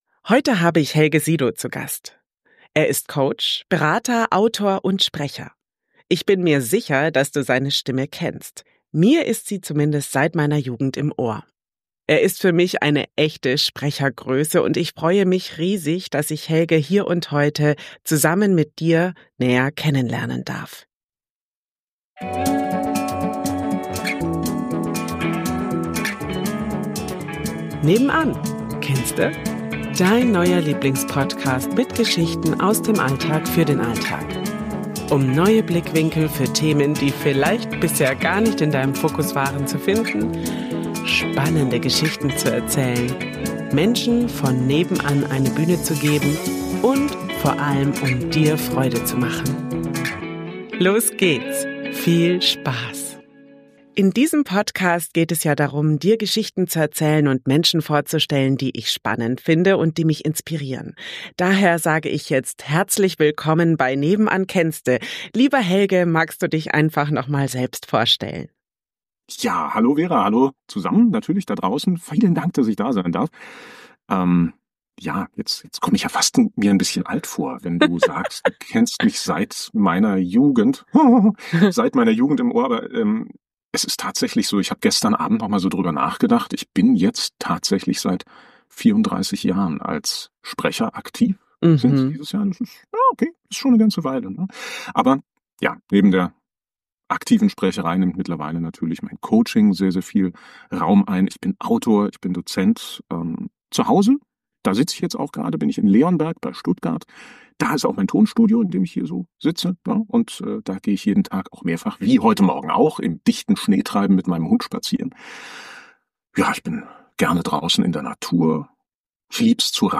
Ich kenne seine Stimme schon ewig.